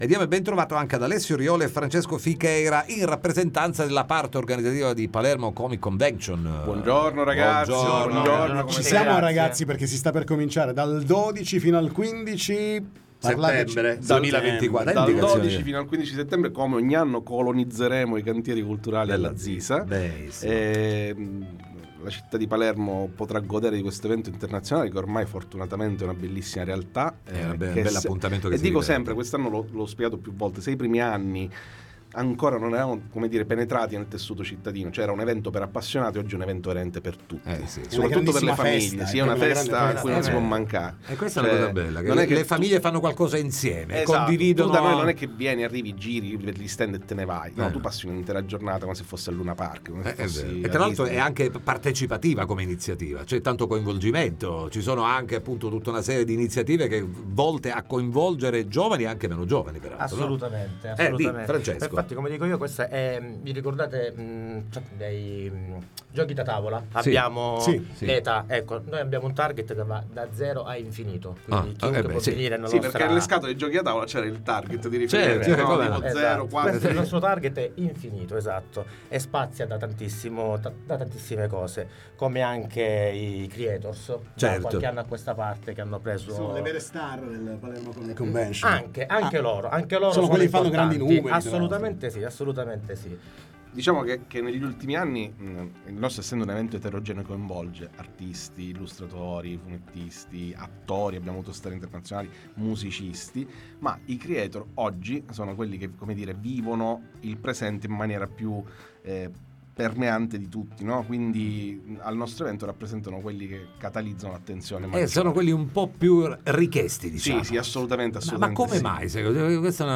Palermo Comic Convention 2024, ne parliamo con parte dell’organico nei ns. studi